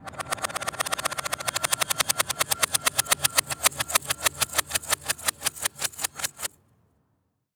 Reverse Metal Clang.wav